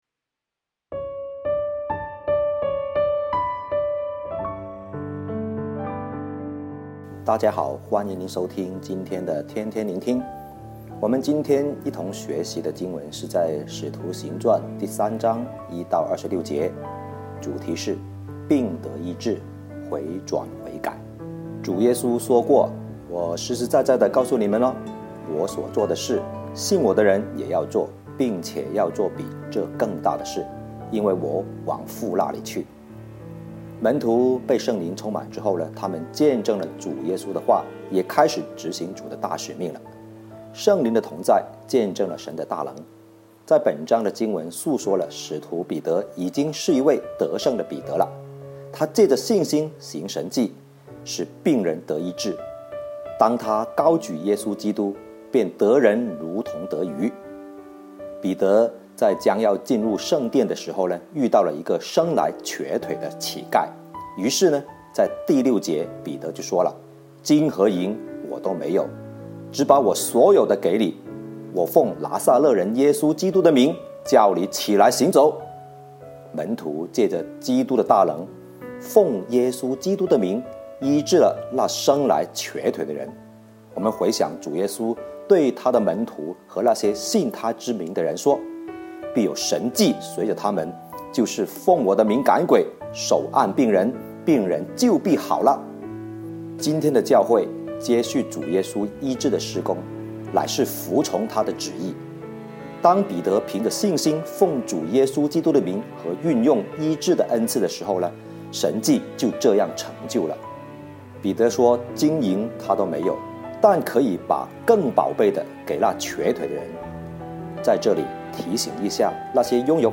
普通话录音连结🔈